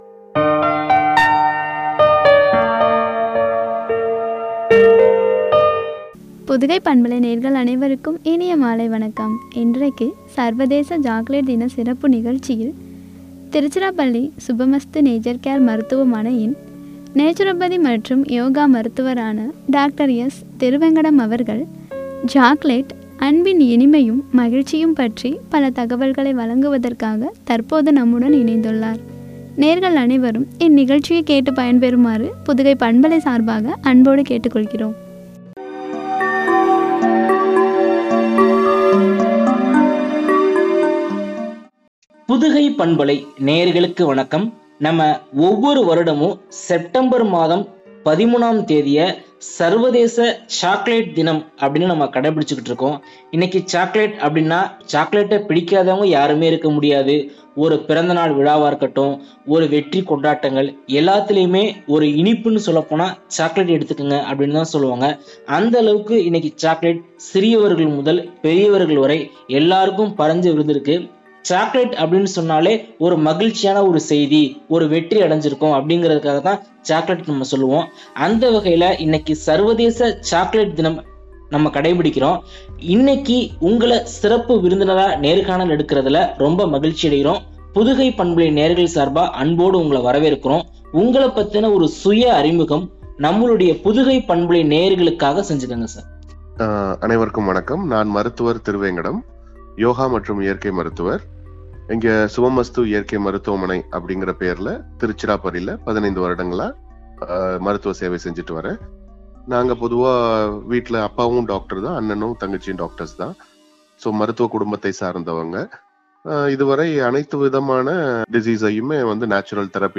மகிழ்ச்சியும்” எனும் தலைப்பில் வழங்கிய உரையாடல்.